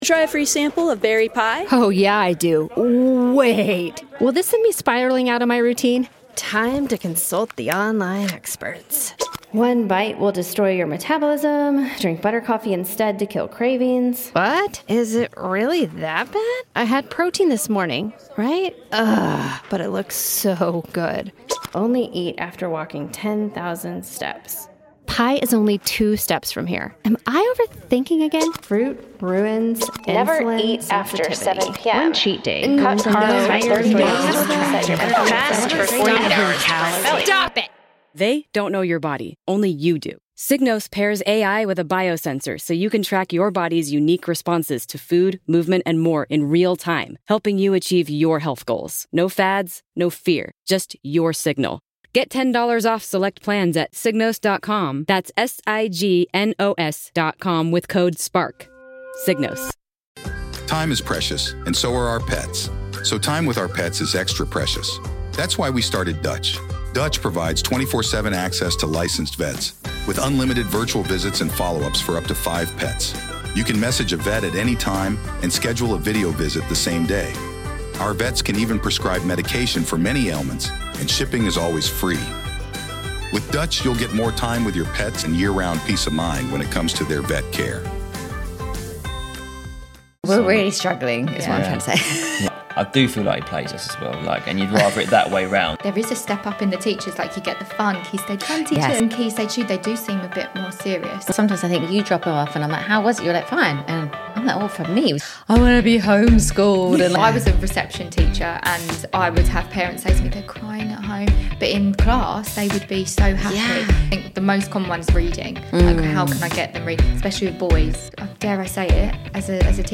- The surprising things parents overthink when it comes to school prep Whether your child is eager to get back into the classroom or dragging their heels, this conversation is packed with tips to help you—and them—navigate the new term with confidence.